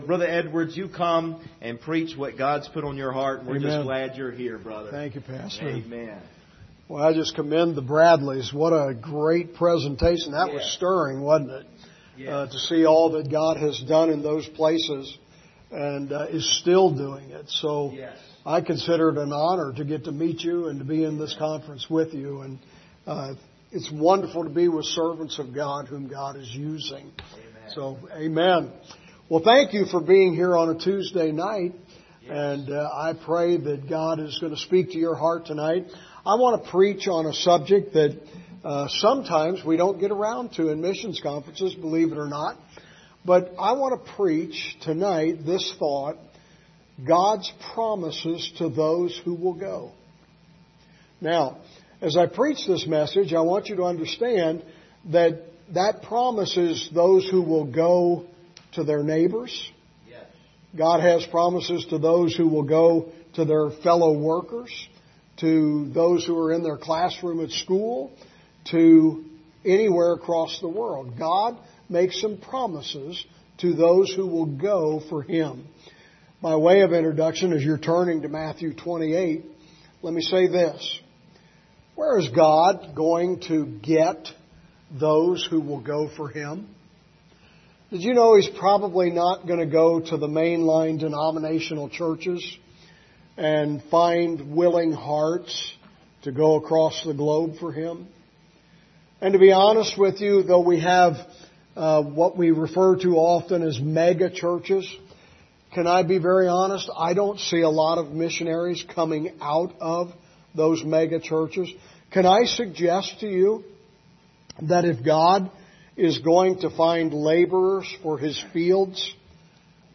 Series: 2022 Missions Conference
Service Type: Special Service